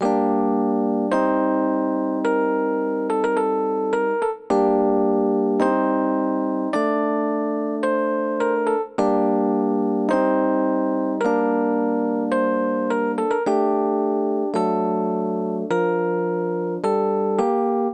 Rhodes 2 Riches 107 Gmin.wav